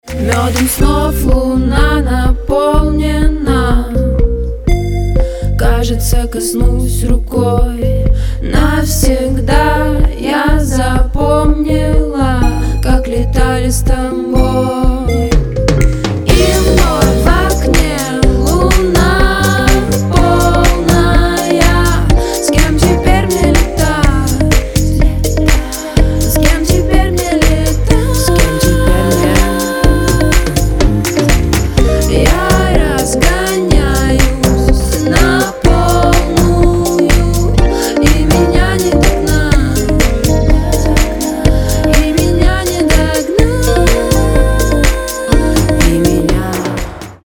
женский вокал
dance
Electronic